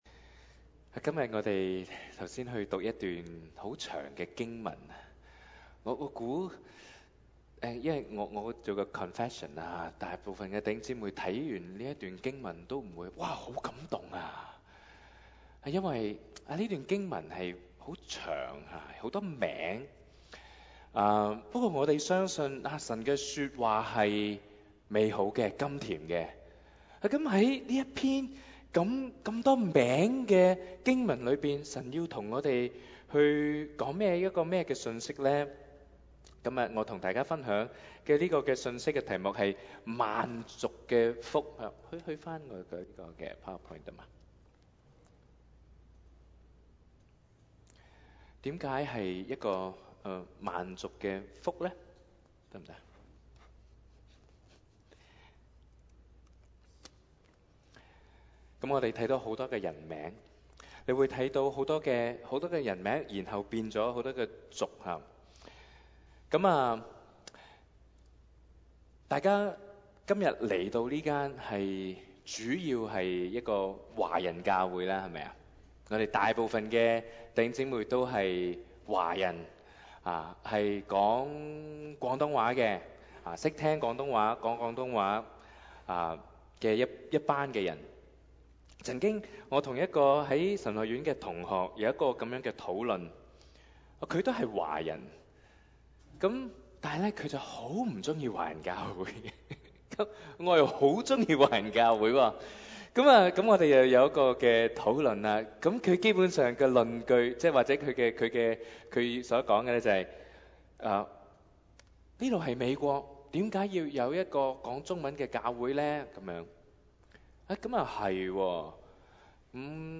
7/10/2022 粵語崇拜講題: 「萬族的福」